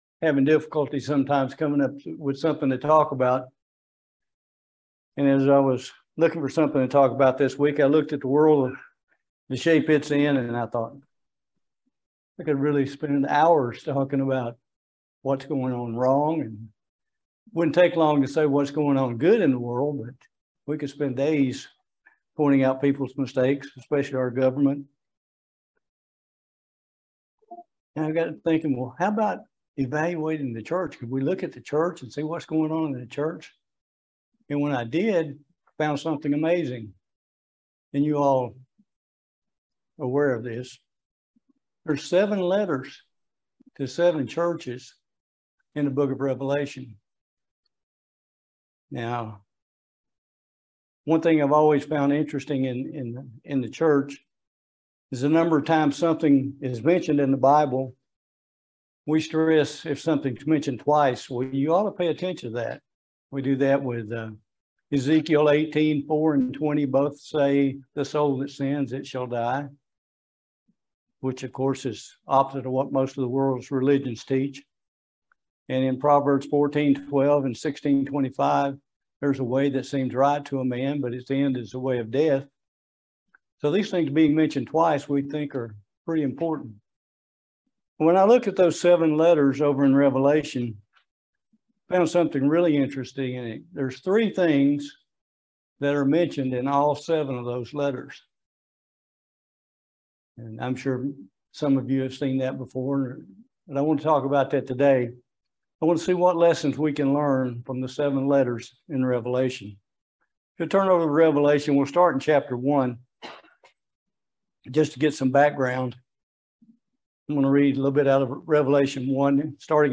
Very eye opening look at the seven letters to the seven Church's in the book of Revelation. Join us for this excellent video sermon on the seven letters.
Given in Lexington, KY